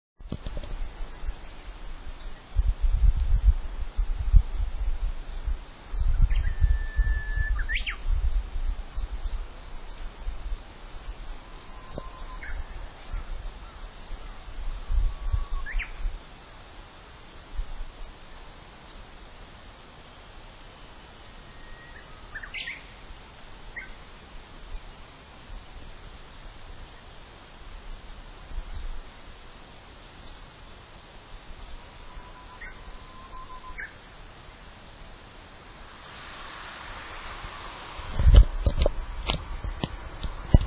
屋久島・「ホーホケキョ」沖縄・音楽ユニット「三四郎」台湾・お茶を煎れるご主人台湾・夜市のざわめきと揚琴の音色台湾・老人の雑談香港・路上で楽器を奏でる老婆中国・地下道の笛吹きベトナム・慰霊碑の前で唱えられるお経
voice_uguisu.mp3